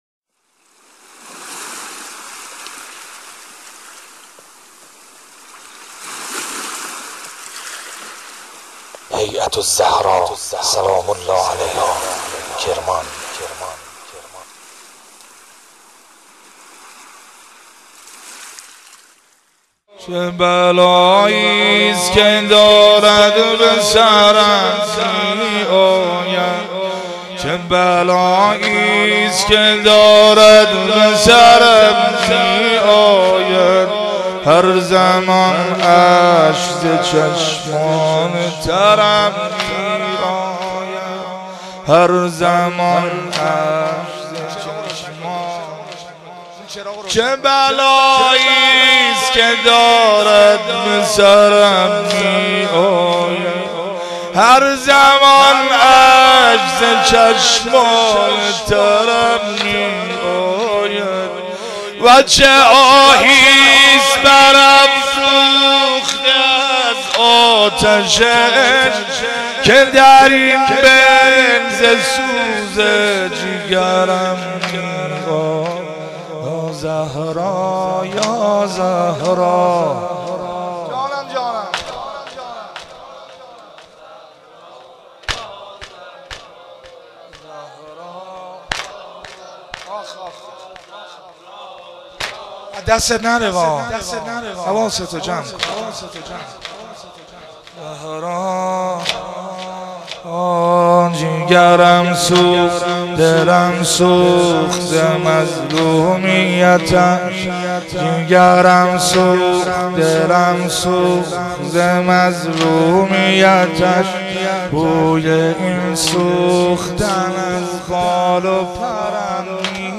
سینه زنی حضرت زهرا